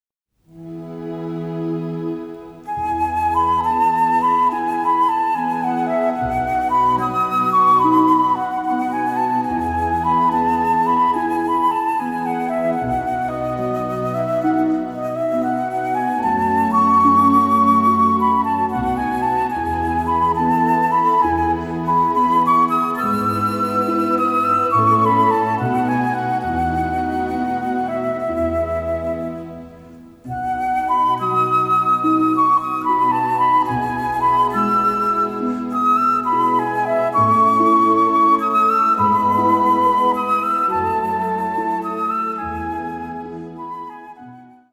a delicate and poetic score
Remastered from the scoring session tapes